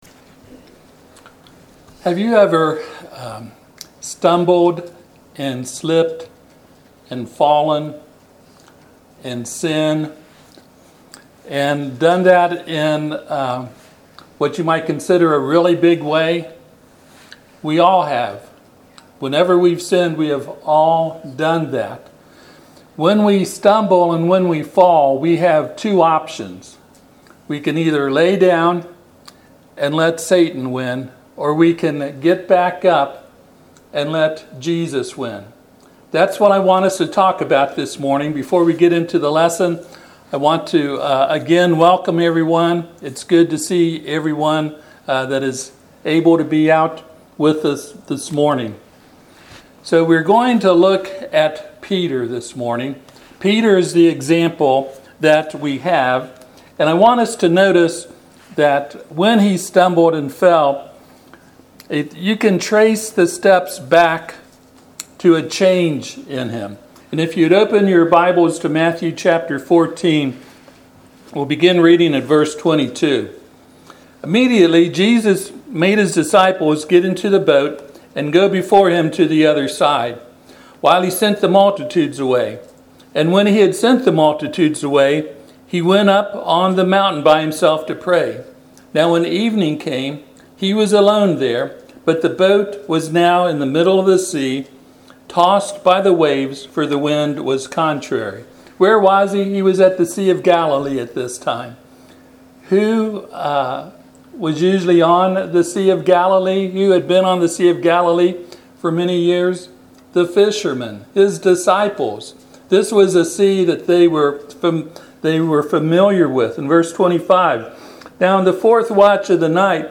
Passage: Mark 14:27-31 Service Type: Sunday AM